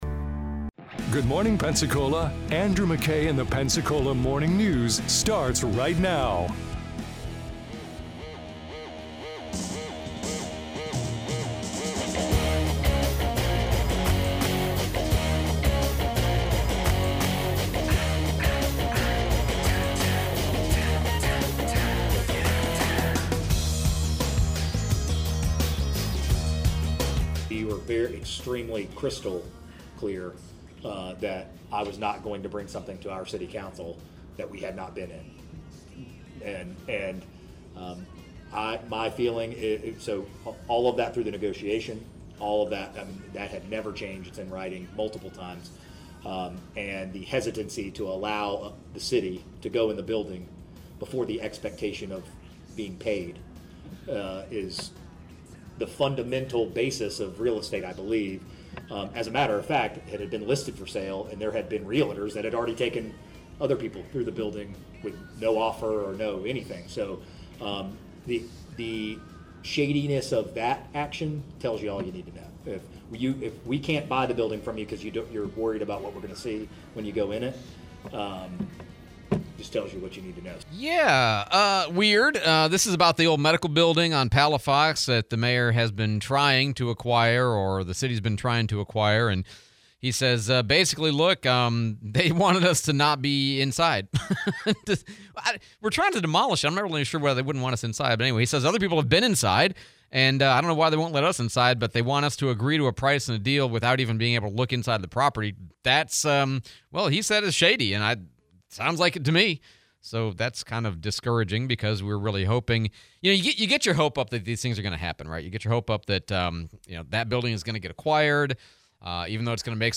Baptist hospital demolition, interview